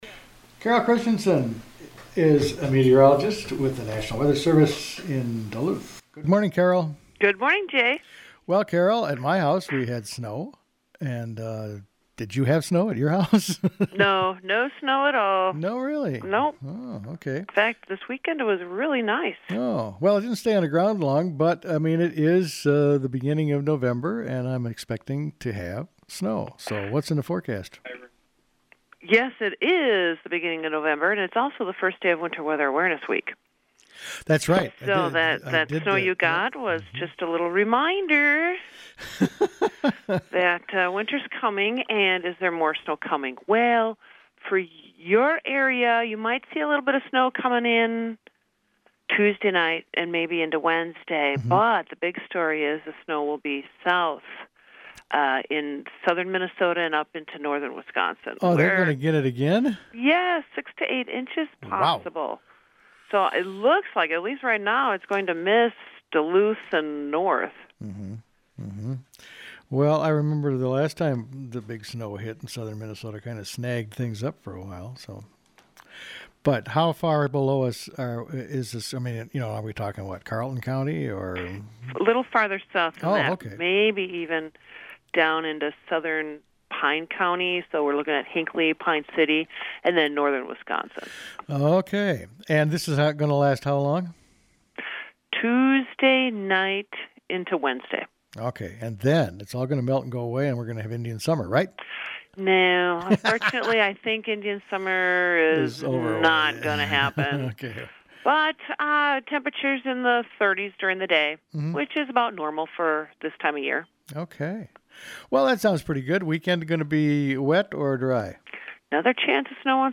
spoke with meteorologist